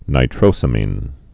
(nī-trōsə-mēn, nītrōs-ămēn)